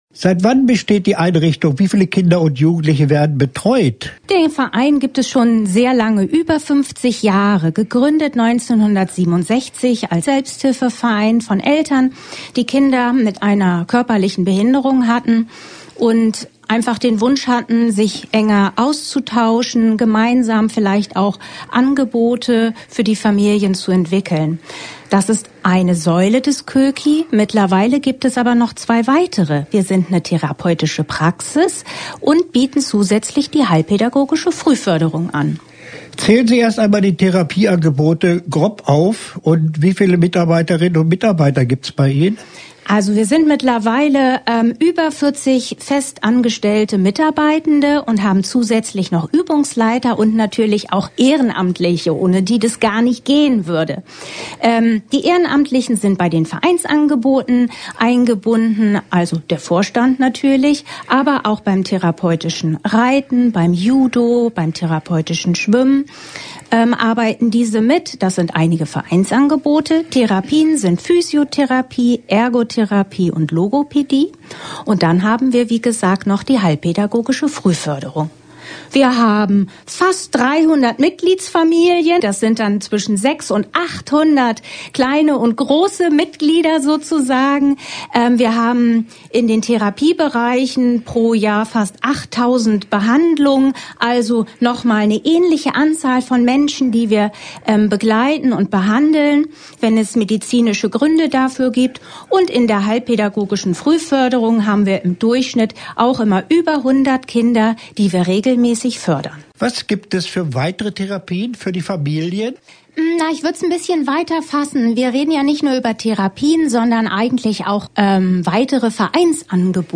Interview-Koeki.mp3